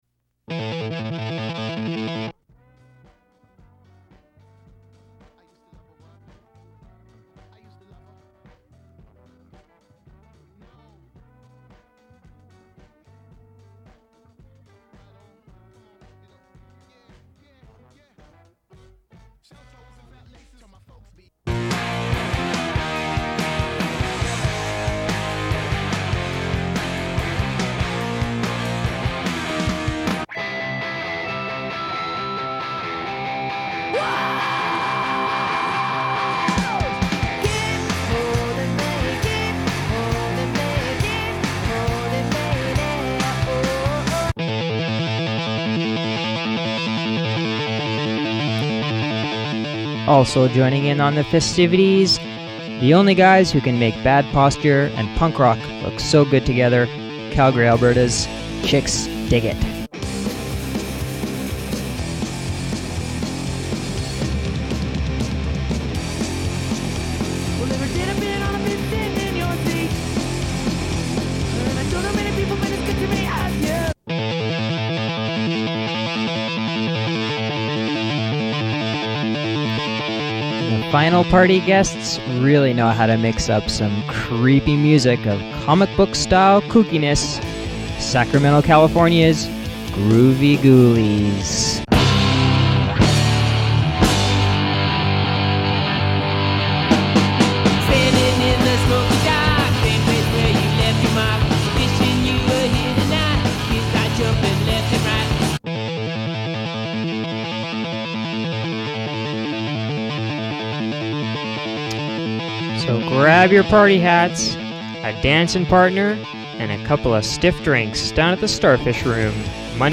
Recording of a promotional spot advertising a performance by musical groups The Muffs, Chixdiggit and Groovie Ghoulies.